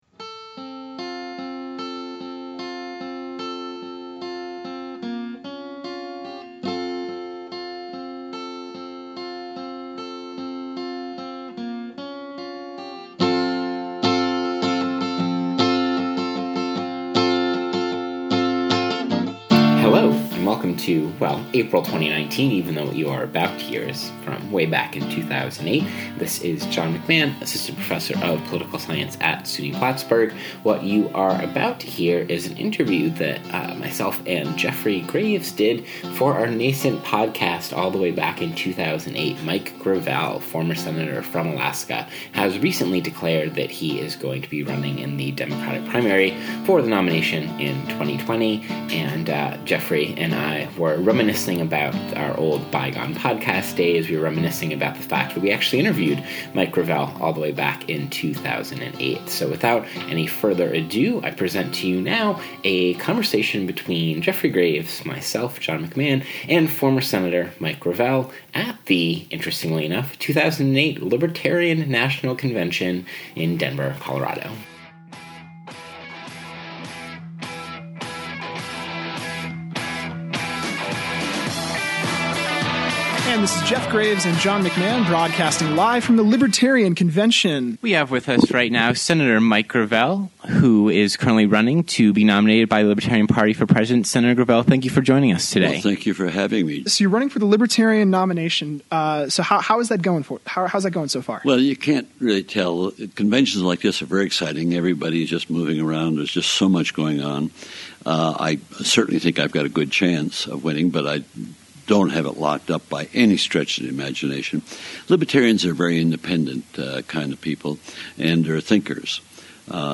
Audio: 2008 Interview with Mike Gravel
gravelinterview.mp3